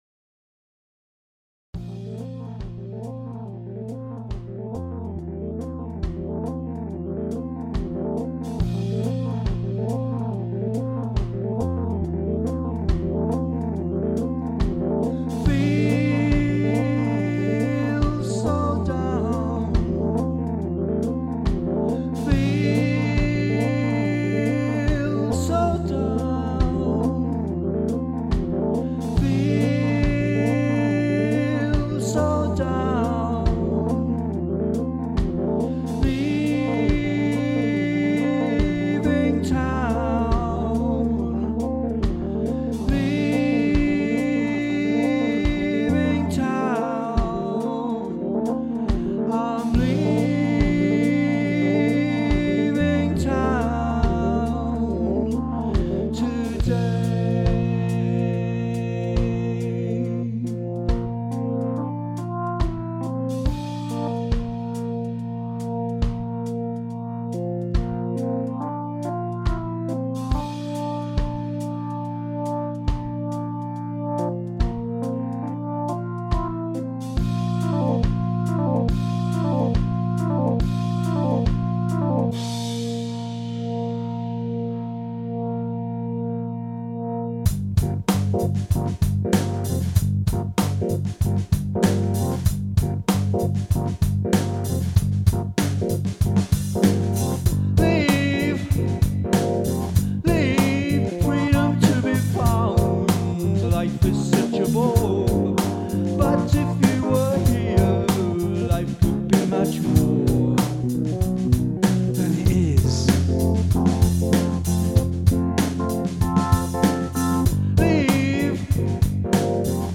7:43/92,70,96,138bpm